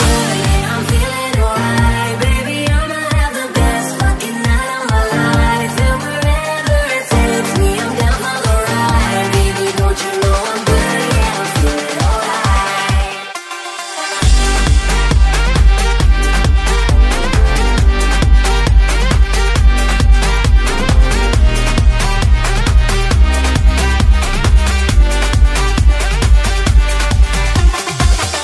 Genere: afrobeat,afrohouse,deep,remix,hit